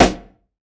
SNARE 051.wav